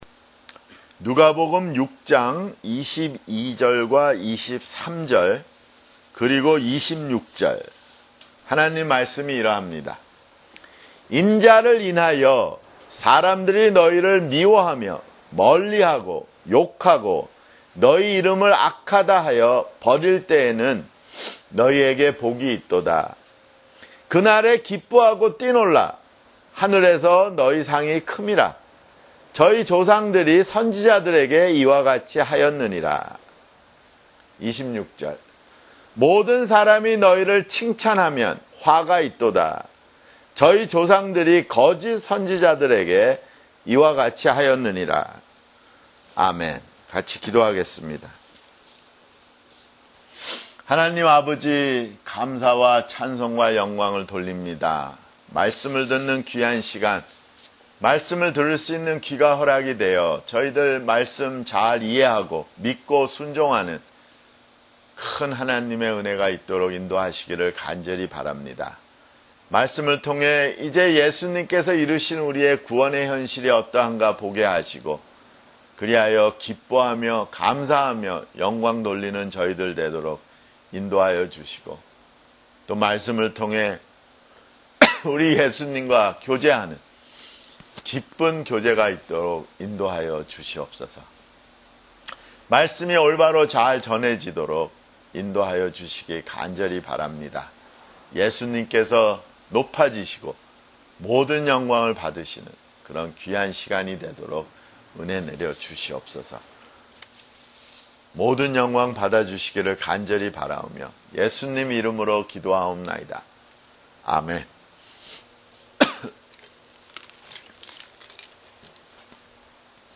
[주일설교] 누가복음 (44)